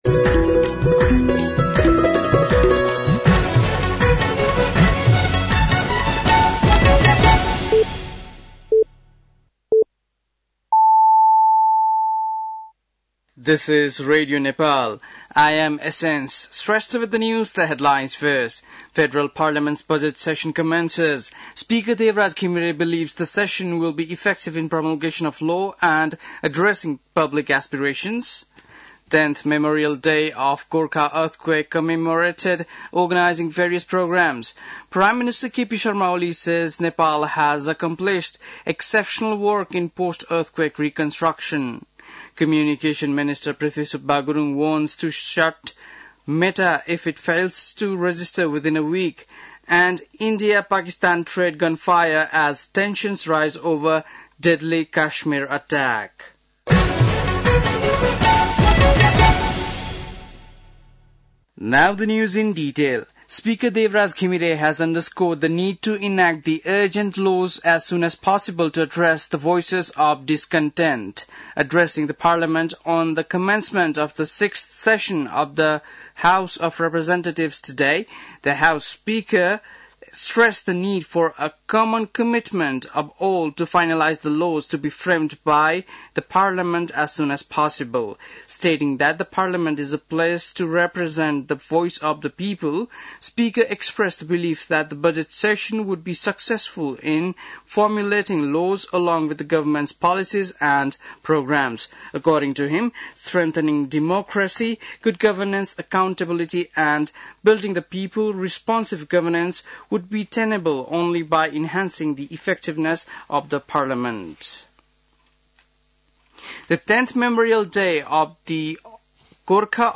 An online outlet of Nepal's national radio broadcaster
बेलुकी ८ बजेको अङ्ग्रेजी समाचार : १२ वैशाख , २०८२